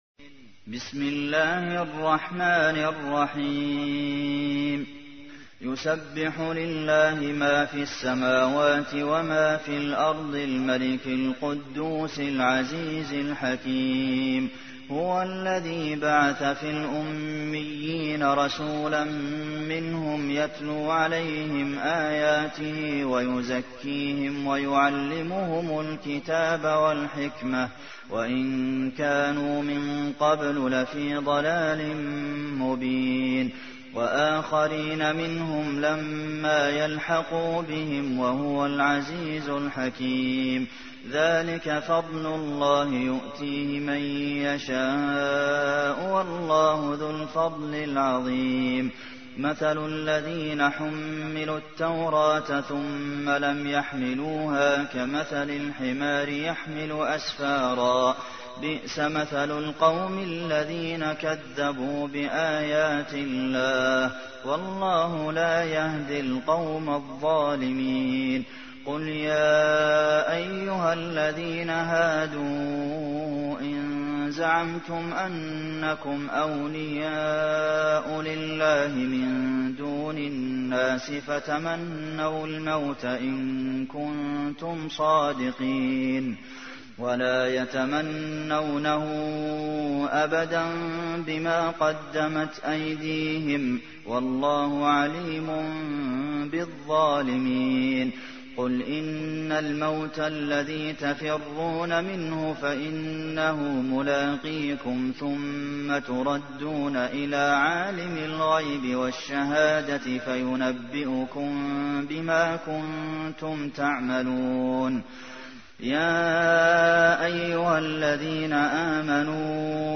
تحميل : 62. سورة الجمعة / القارئ عبد المحسن قاسم / القرآن الكريم / موقع يا حسين